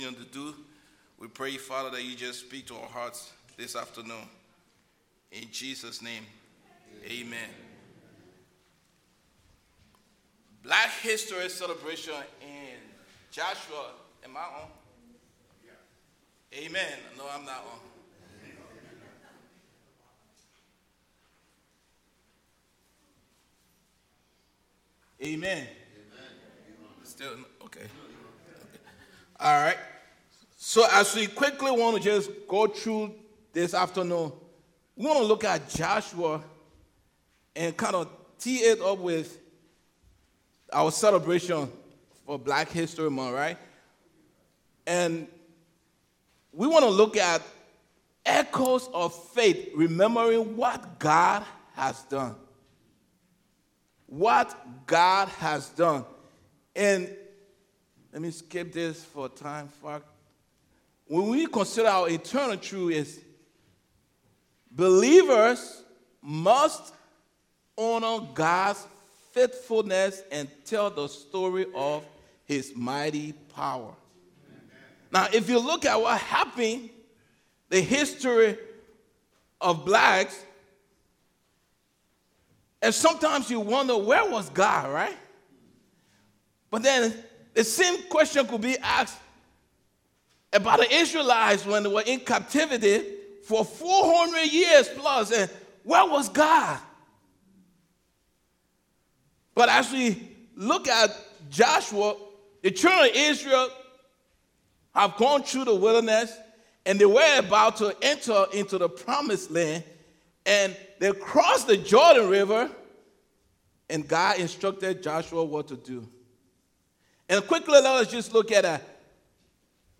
Sermons | First Baptist Church of Willingboro, NJ
2-22-26   Black History Month Celebration  ''Echoes of Faith Remembering What God Has Done...''.mp3